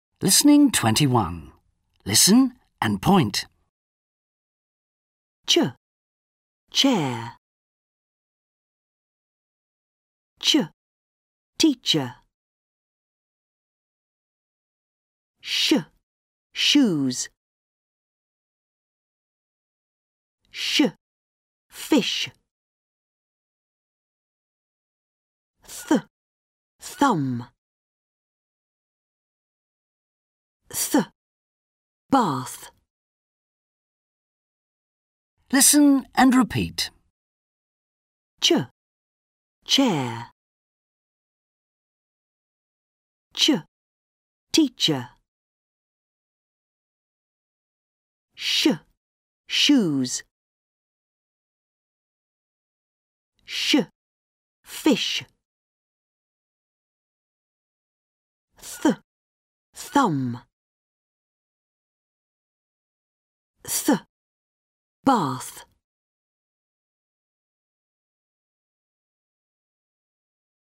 • Lesson 4: Phonics